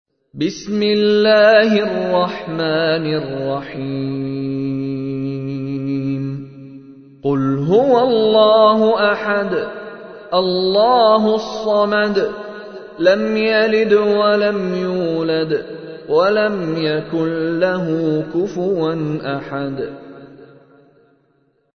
تحميل : 112. سورة الإخلاص / القارئ مشاري راشد العفاسي / القرآن الكريم / موقع يا حسين